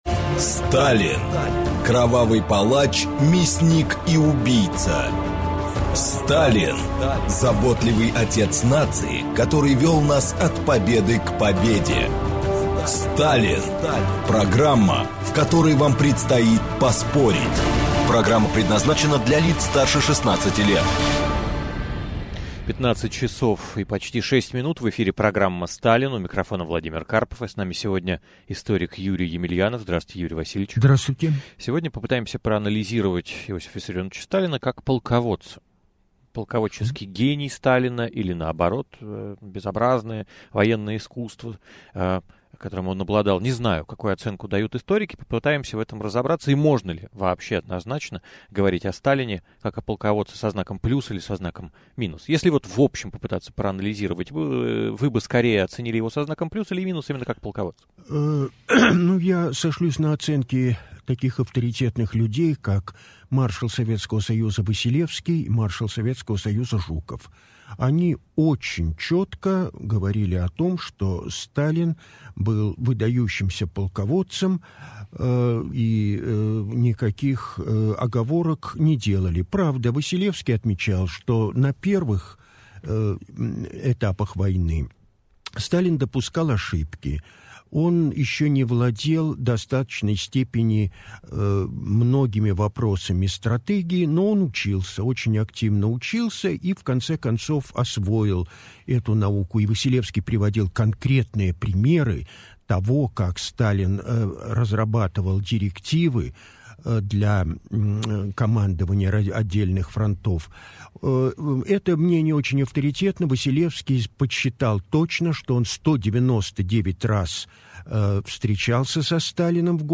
Аудиокнига Сталин-полководец | Библиотека аудиокниг